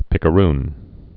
(pĭkə-rn)